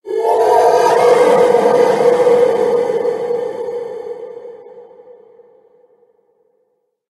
Cri de Sorcilence Gigamax dans Pokémon HOME.
Cri_0858_Gigamax_HOME.ogg